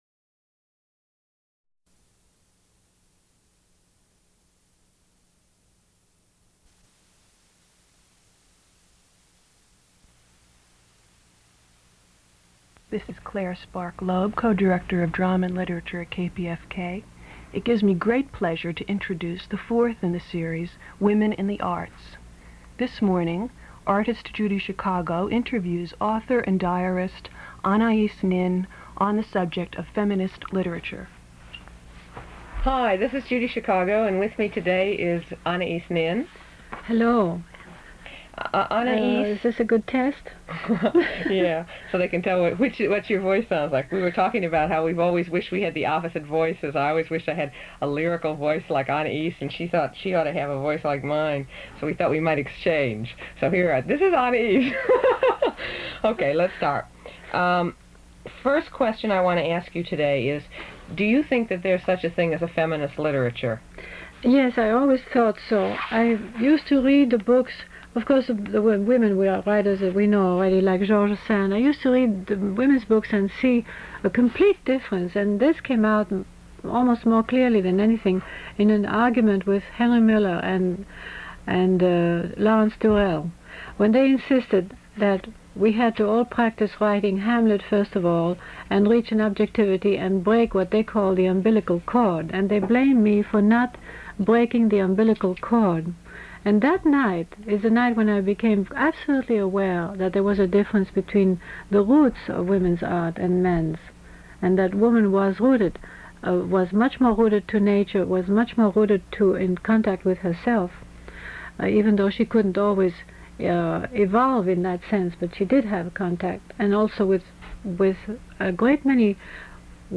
Judy Chicago hosts and interviews author Anaïs Nin on the subjects of feminist literature and women's liberation. They discuss a disagreement they previously had about anger and women's liberation. Anaïs also reads from and discusses her book, Fourth Diary .
Radio talk shows